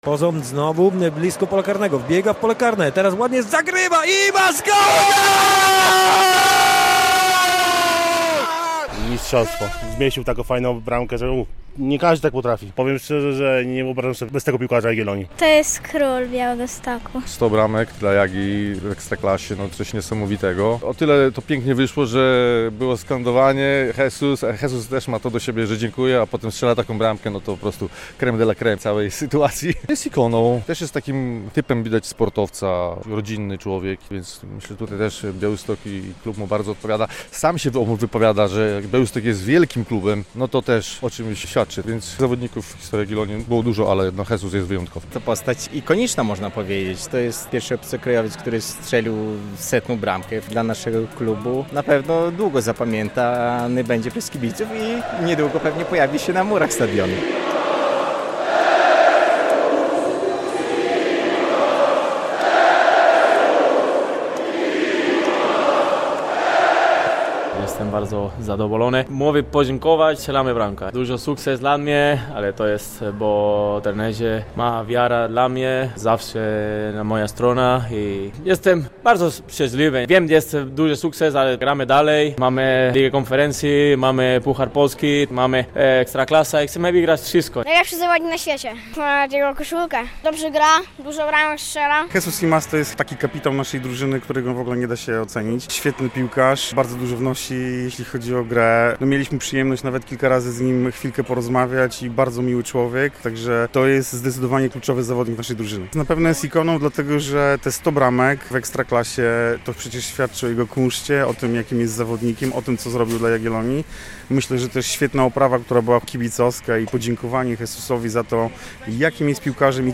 100 bramek Jesusa Imaza w Jagiellonii Białystok - relacja